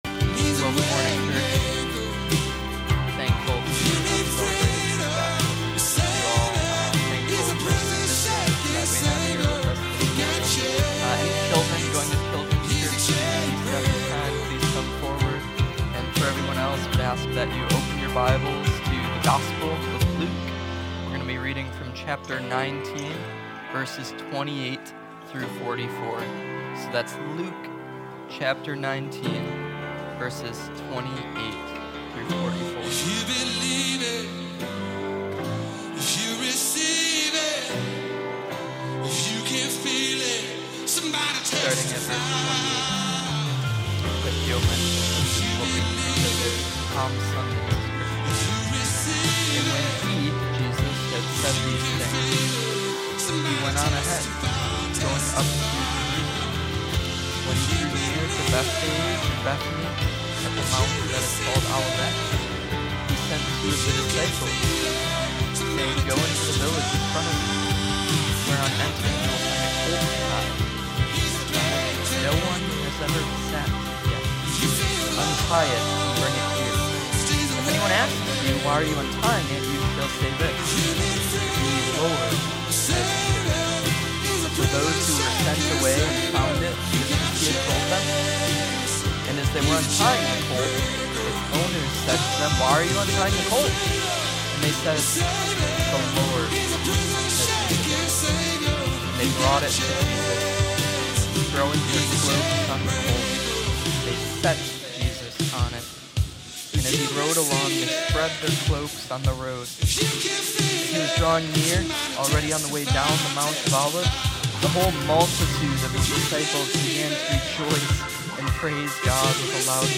This Sermon explores four key aspects of His entrance: